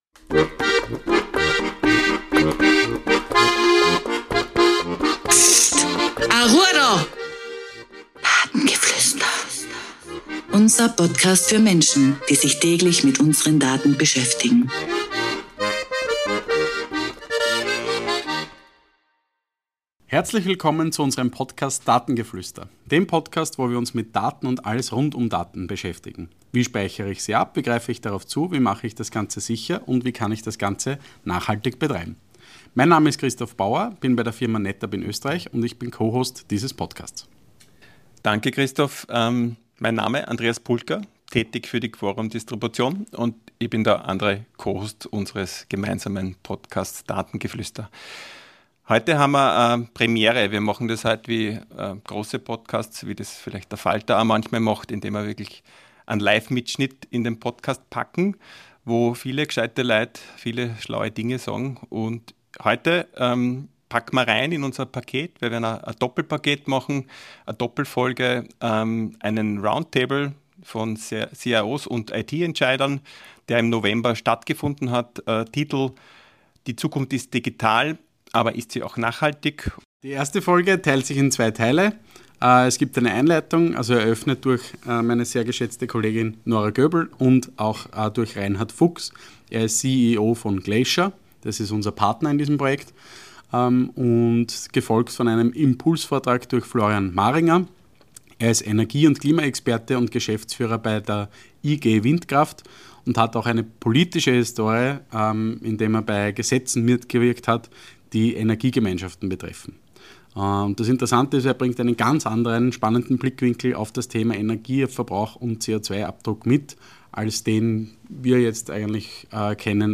In dieser Spezialfolge von Datengeflüster hört ihr den ersten Teil (von 2) eines Live-Mitschnitts unseres CIO-Roundtables von Glacier der innerhalb der Kooperation mit NetApp am 20.11.2025 in Wien stattgefunden hat.